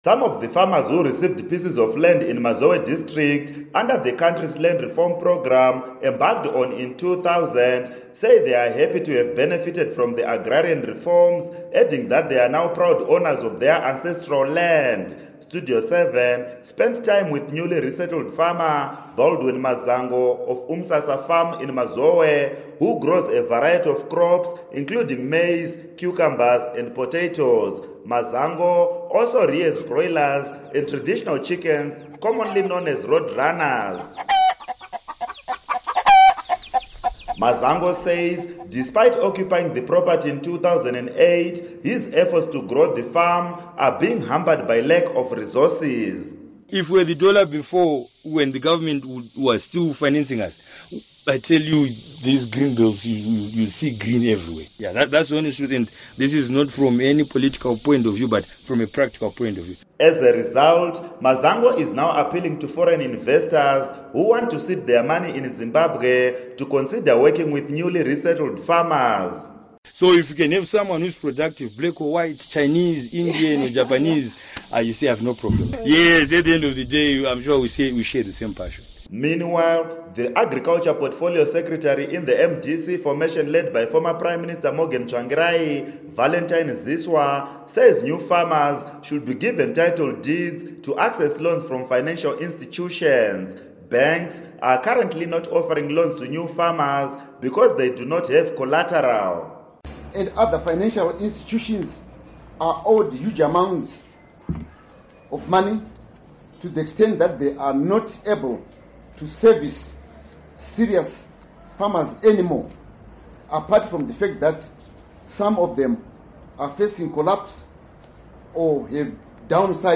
Report on New Farmers